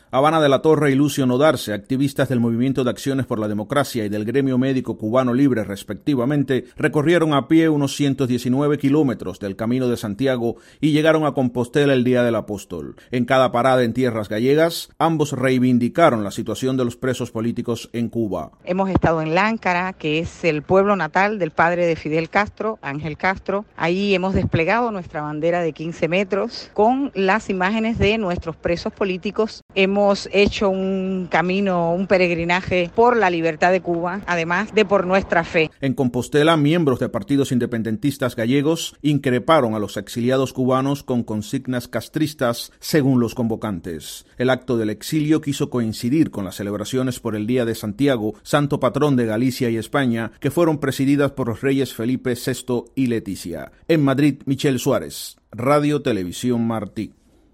Reporte desde Madrid